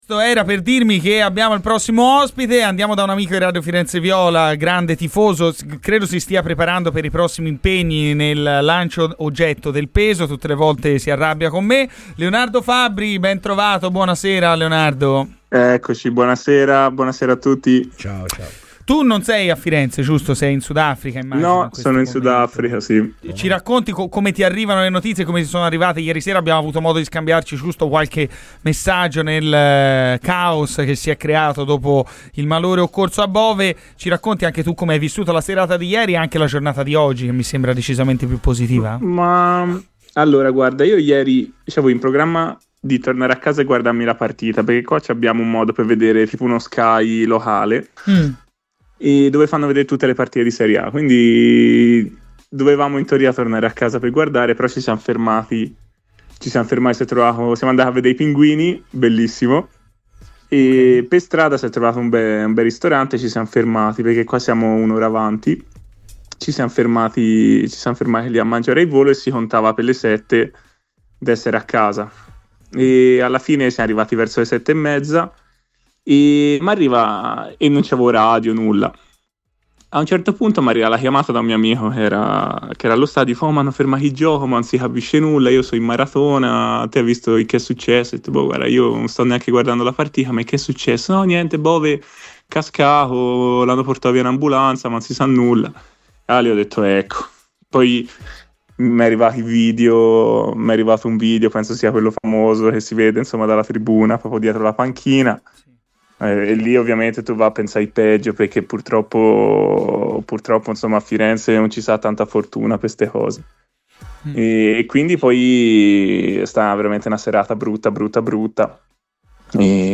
Leonardo Fabbri, pesista fiorentino e grande tifoso viola, è intervenuto ai microfoni di Radio FirenzeViola, durante "Garrisca al vento", per parlare di Edoardo Bove: "Io al momento mi trovo in Sud Africa che rispetto all'Italia è un'ora avanti.